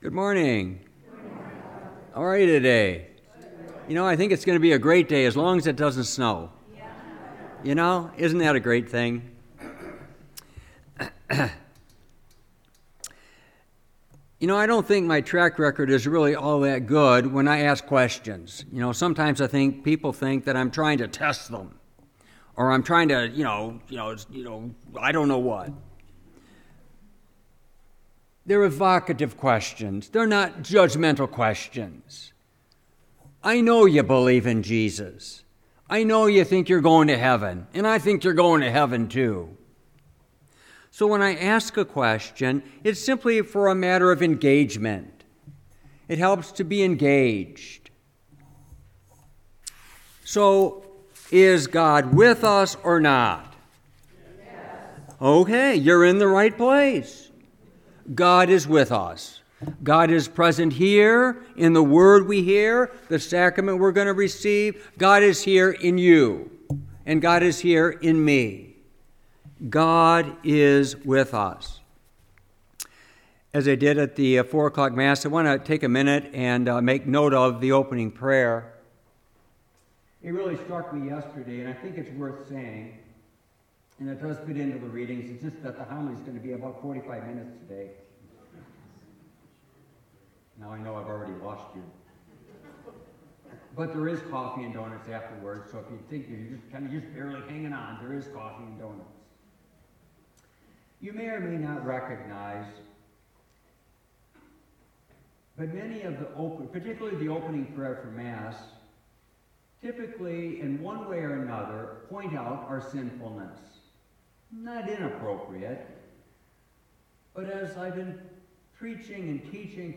Homily, 3rd Sunday of Lent – March 12, 2023
Homily-3rd-SOL23.mp3